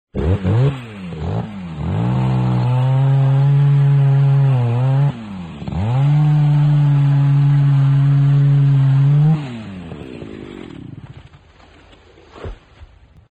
chainsaw.wav